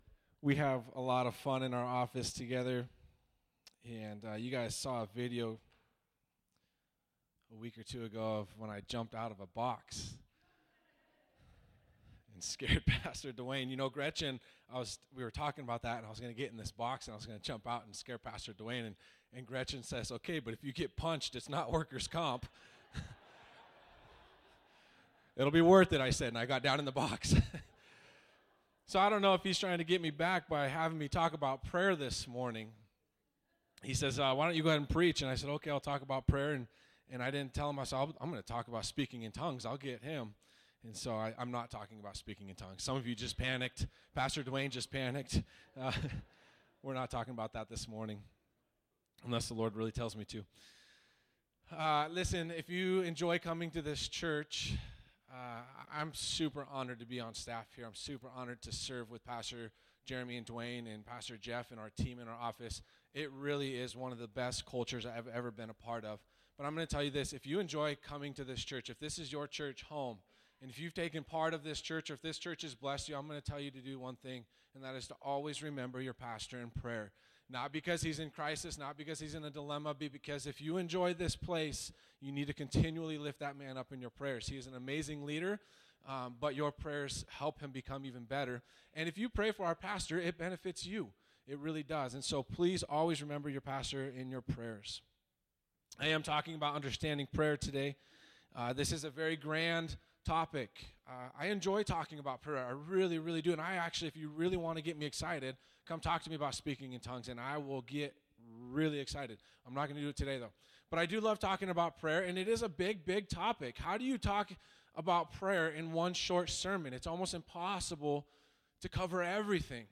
Sermons - Redmond Assembly of God